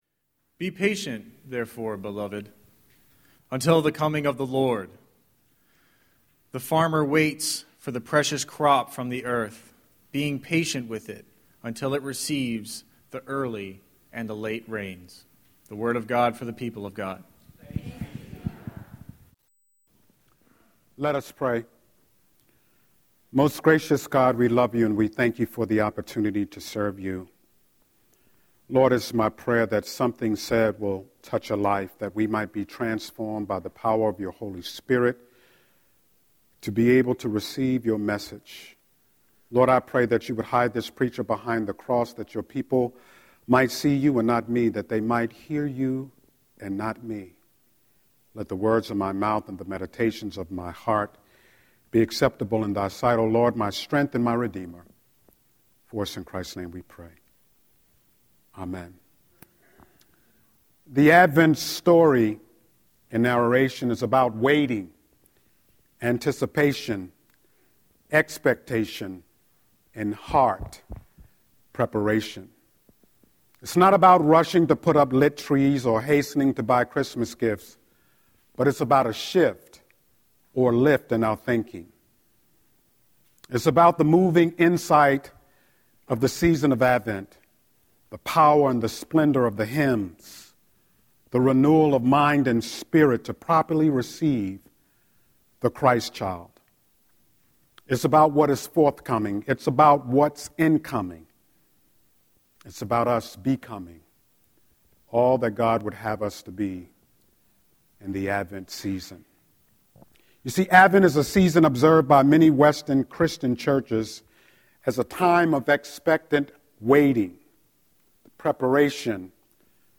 11-29-Scripture-and-Sermon.mp3